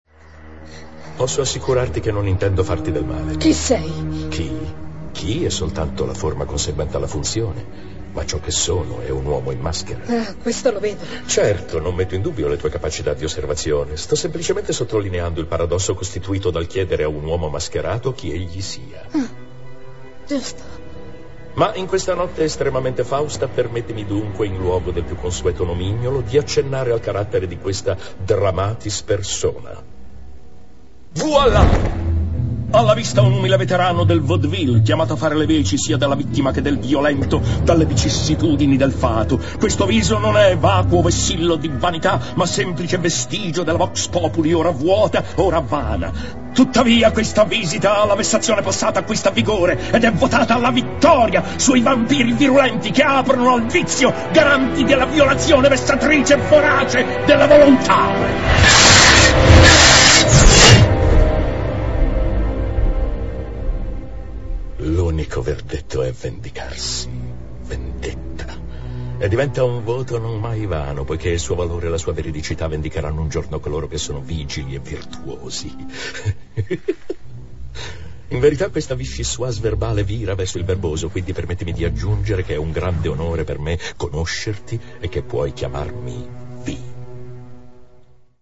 voce di Gabriele Lavia nel film "V per Vendetta", in cui doppia Hugo Weaving.